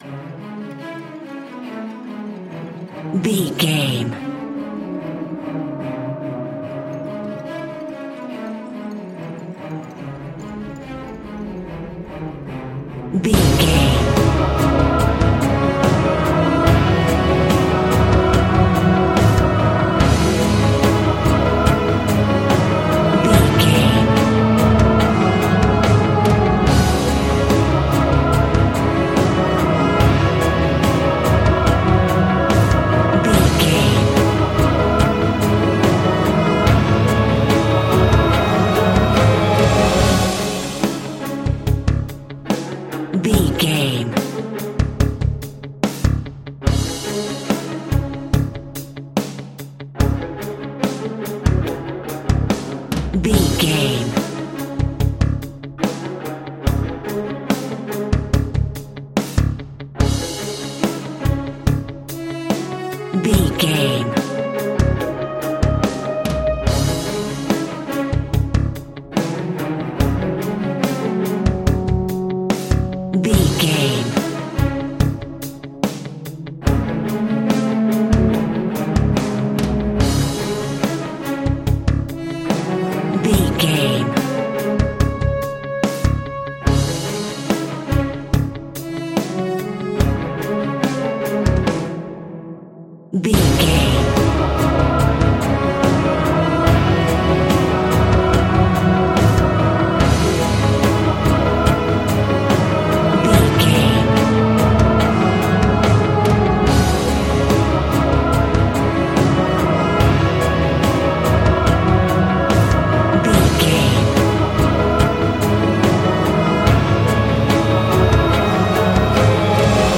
In-crescendo
Thriller
Aeolian/Minor
C#
ominous
suspense
dramatic
haunting
driving
intense
powerful
strings
brass
percussion
cinematic
orchestral
heroic
taiko drums
timpani